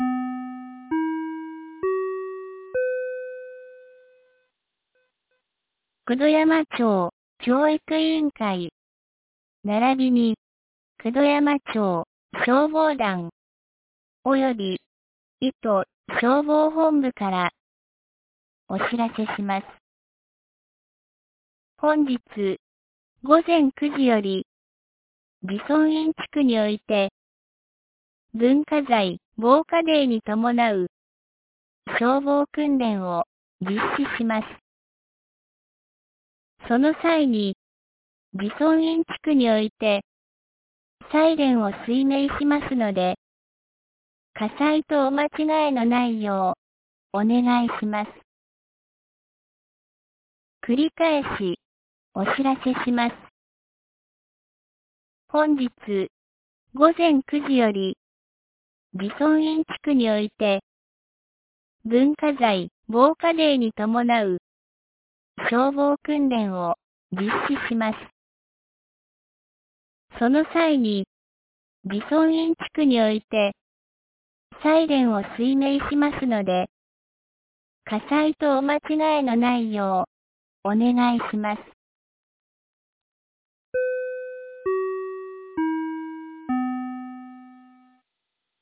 2025年01月26日 08時46分に、九度山町より九度山地区、入郷地区、慈尊院地区へ放送がありました。